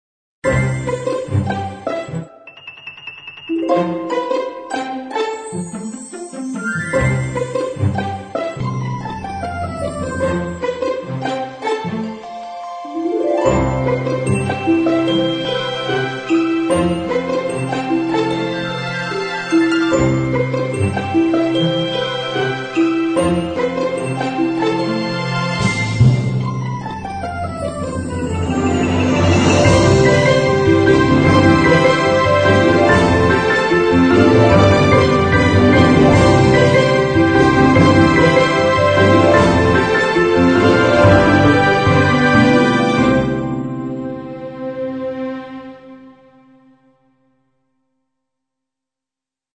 描述：好玩的管弦乐适合用于广告和家庭电影。
Looped Audio 循环音频No 没有
Sample Rate 抽样率16位立体声, 44.1 kHz